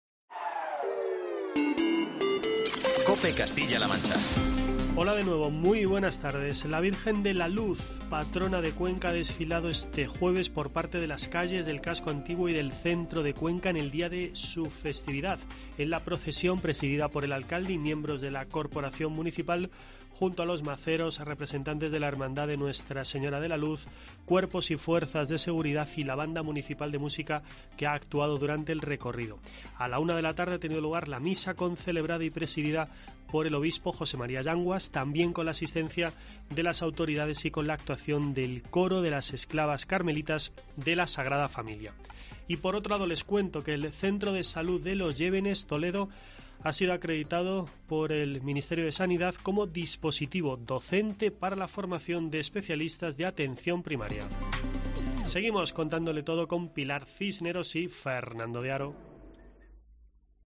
boletín informativo